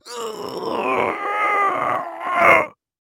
Звуки усилия
На этой странице собраны звуки усилия: стоны, напряжение, дыхание при физической нагрузке.